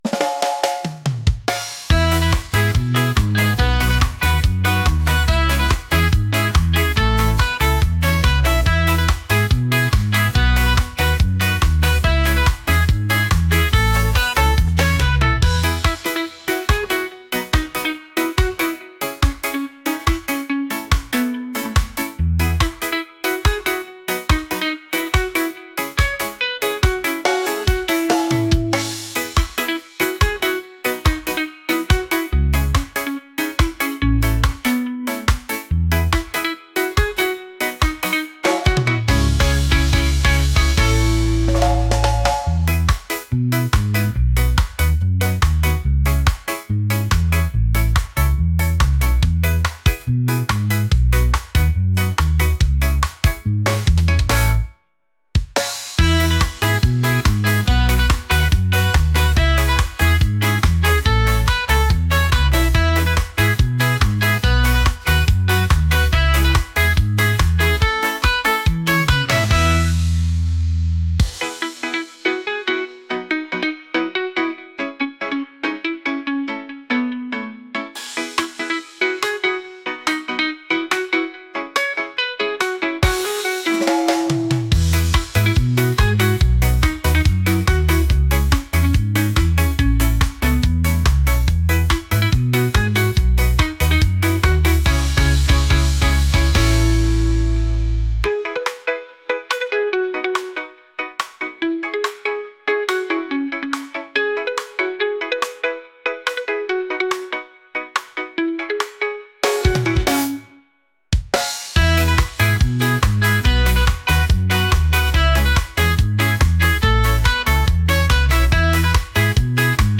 reggae | upbeat | catchy